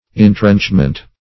Intrenchment \In*trench"ment\, n. [From Intrench.]